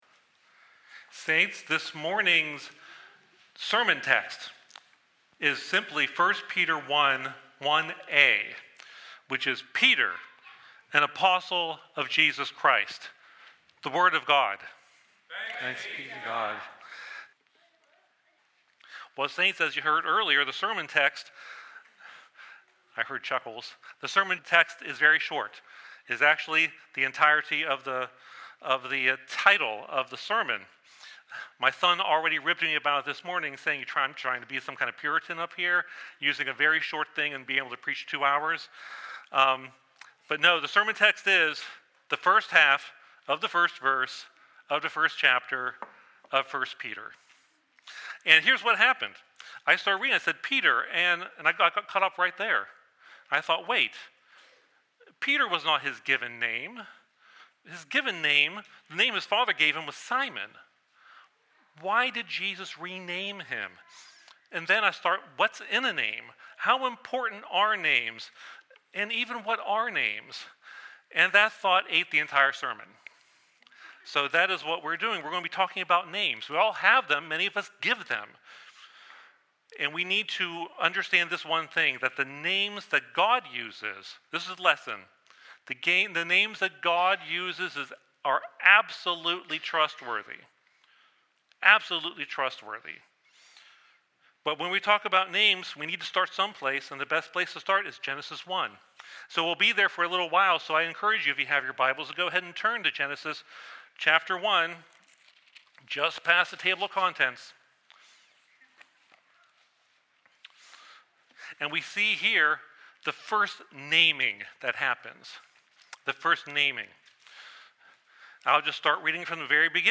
TRC_Sermon-12.1.24.mp3